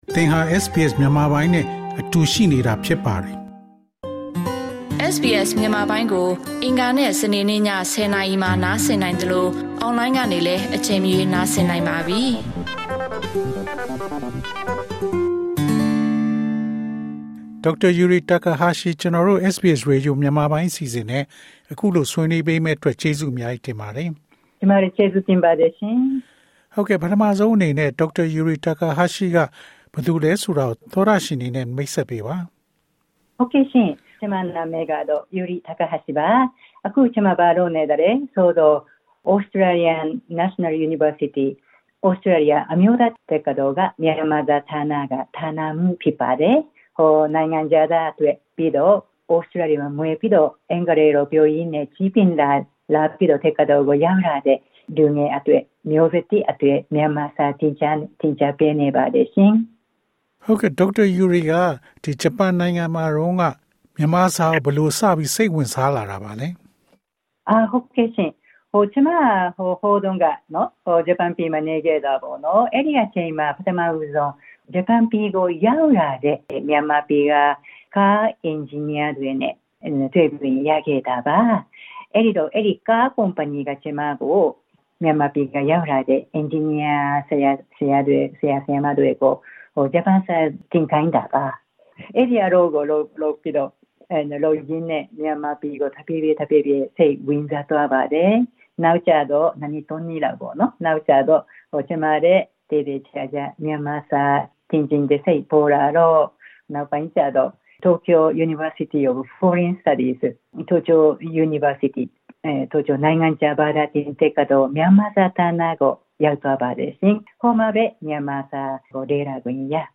တွေ့ဆုံမေးမြန်း။